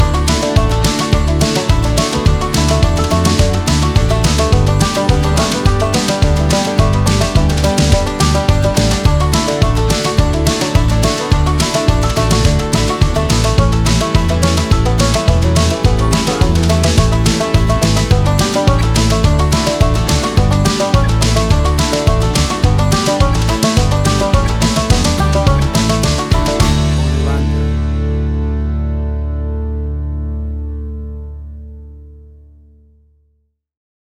A fast and uptempo piece of banjo driven country music.
Tempo (BPM): 106